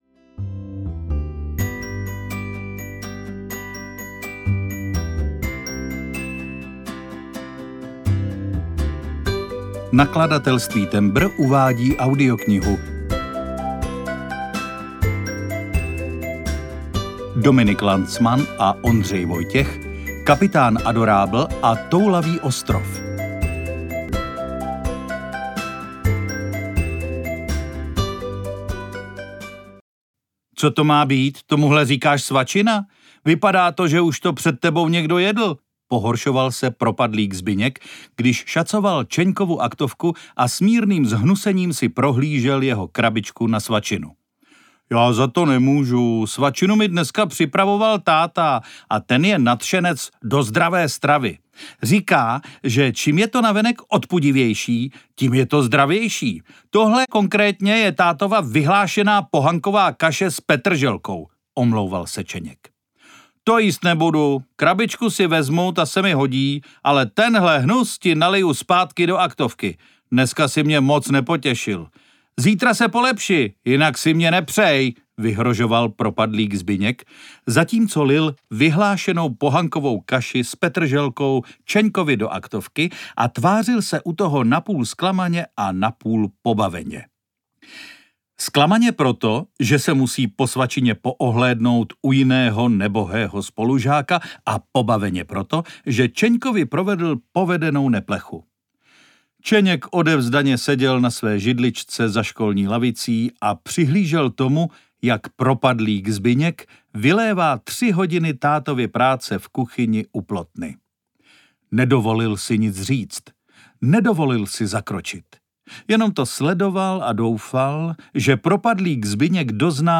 Kapitán Adorabl a Toulavý ostrov audiokniha
Natočeno ve studiu S Pro Alfa CZ